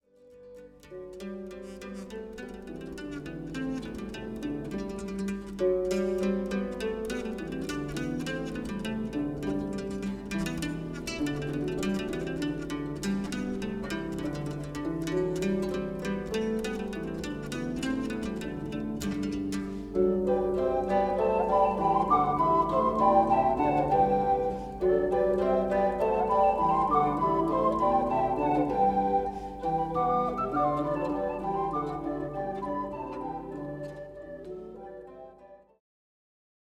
L'Harundo Ensemble'
Ai flauti si affiancano altri strumenti a corde (arpa, chitarra e mandola) e diversi strumenti a percussione.
Suoni e parole di natale: I brani più famosi della tradizione natalizia arrangiati per ensemble di flauti di bambù, alternati a letture di estratti da racconti di vari autori e generi sul Natale.